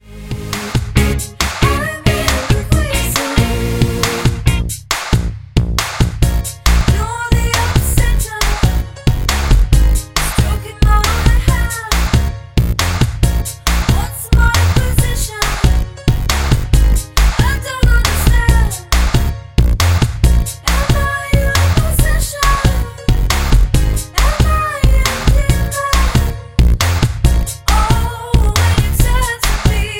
A#
MPEG 1 Layer 3 (Stereo)
Backing track Karaoke
Pop, 2000s